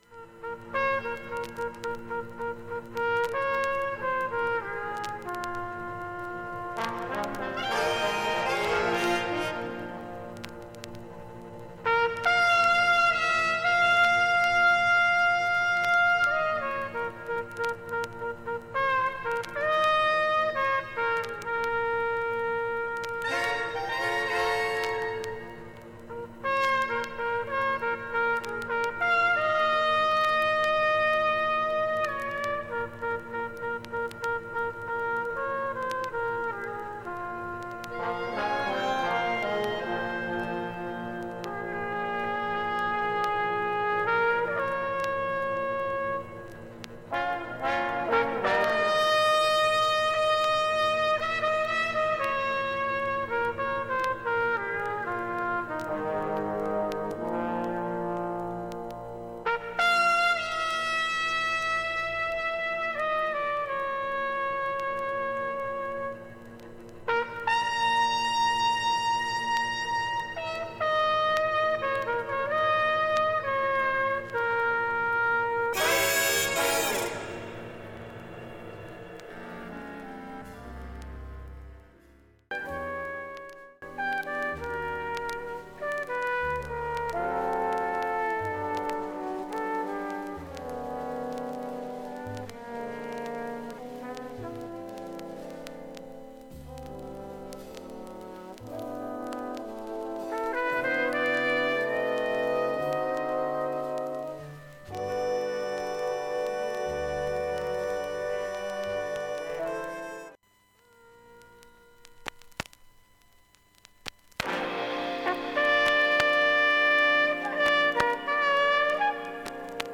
後半は大きめプツプツ出ます。
B-3,4,5,6,7は周回プツプツずっと出ます。
現物の試聴（上記録音時間７分）できます。音質目安にどうぞ
◆ＵＳＡ盤初期プレスSTEREO